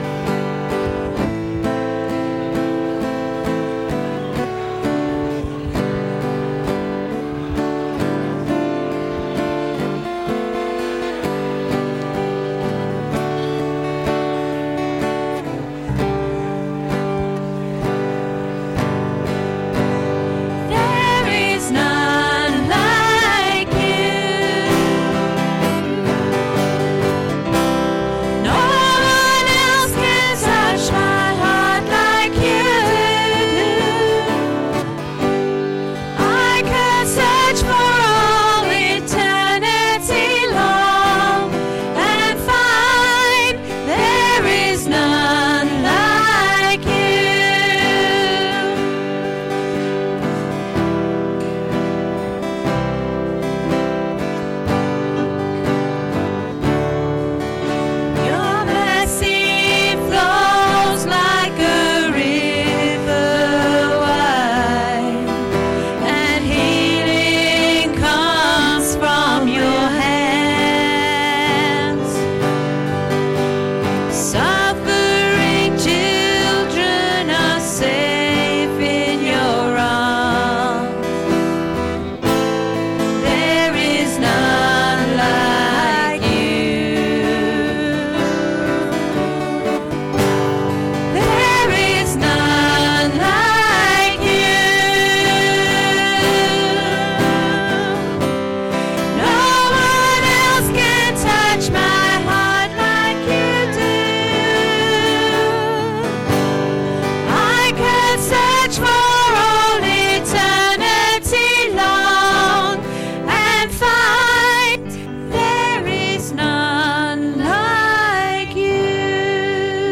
The first in a new sermon series: The Jesus Life. This service will include communion.
Service Audio